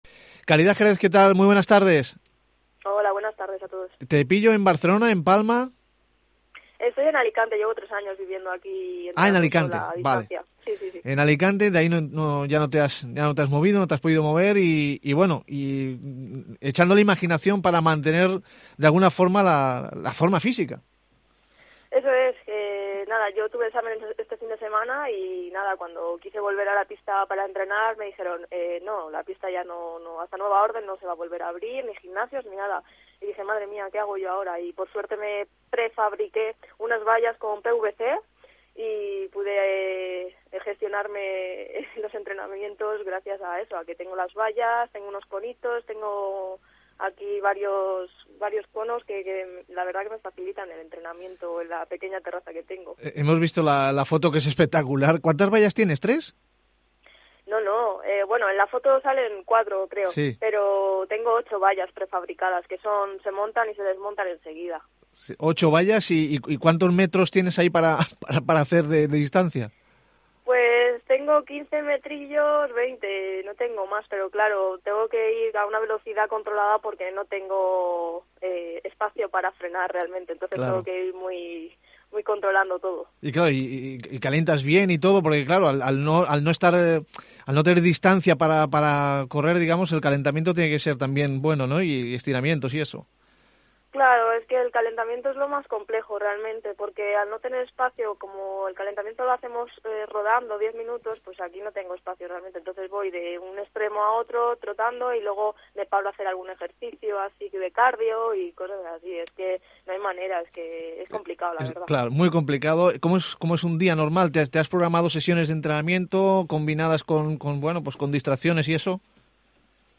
nos explica desde su domicilio cómo lo hace.